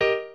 piano5_7.ogg